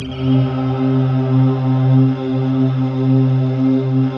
Index of /90_sSampleCDs/Optical Media International - Sonic Images Library/SI1_RainstickChr/SI1_Long Rain
SI1 RAINC00L.wav